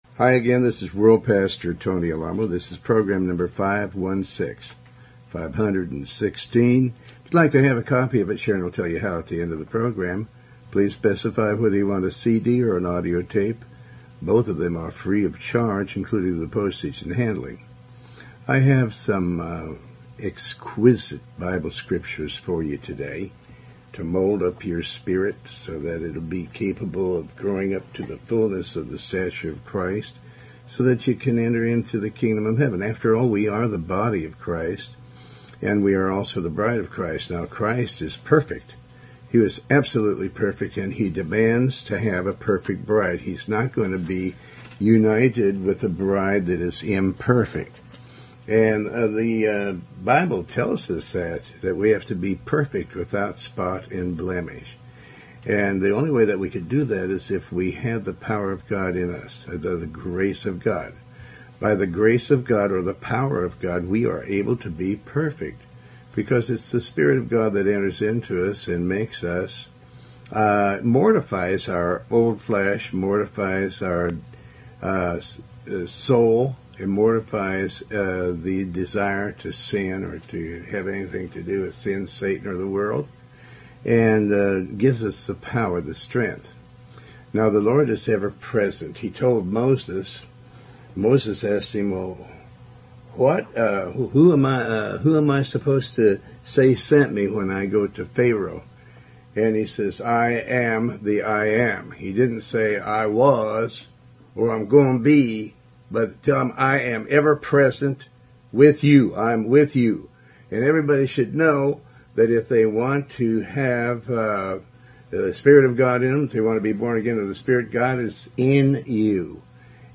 Tony Alamo Talk Show
Show Host Pastor Tony Alamo